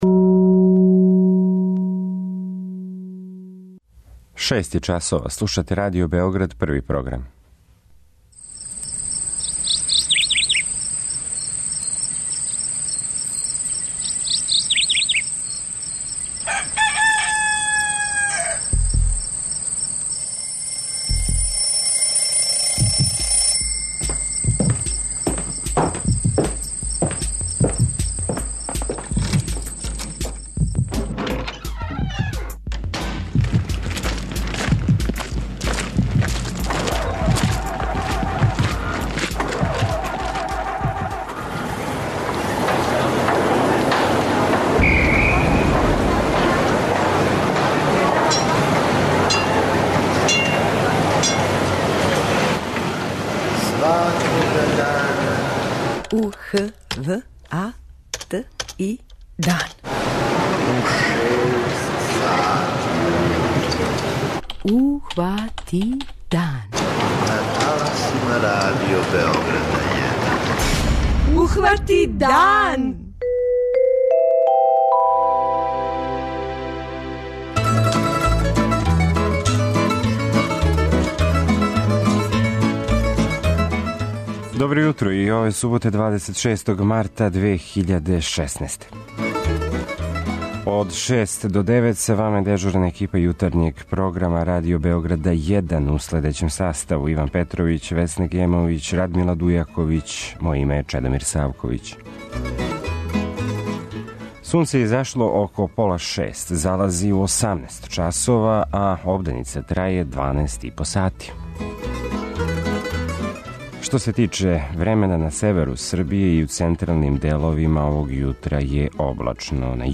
преузми : 85.87 MB Ухвати дан Autor: Група аутора Јутарњи програм Радио Београда 1!